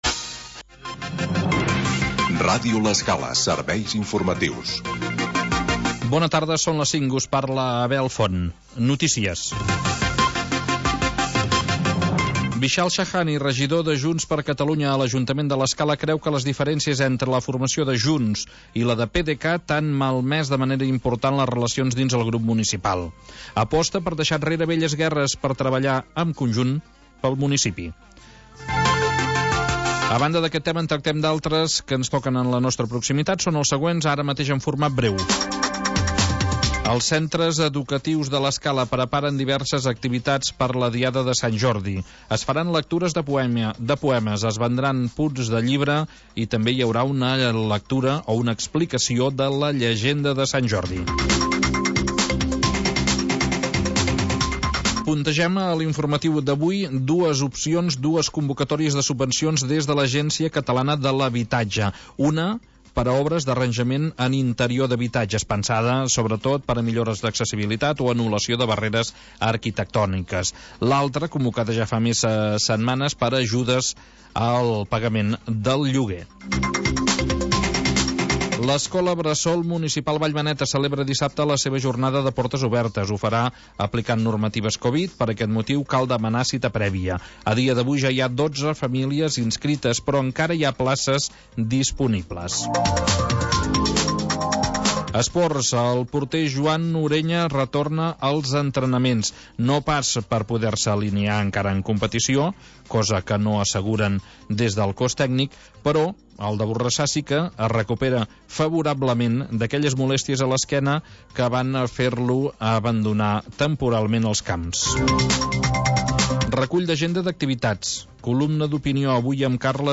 Magazín territorial que parla del mar